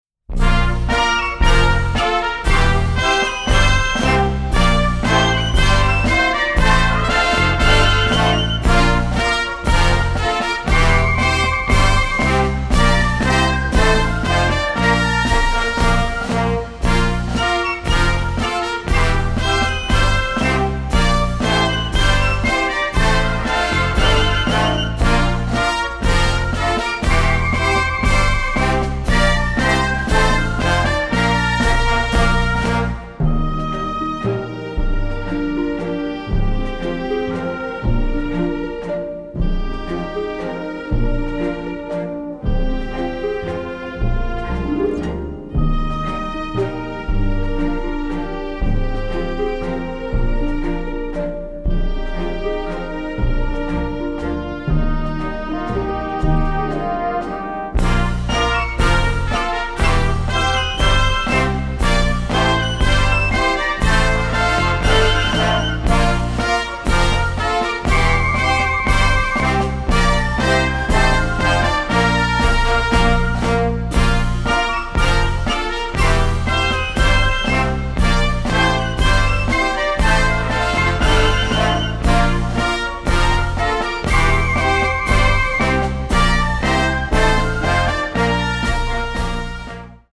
(Mono Stem, Film Take)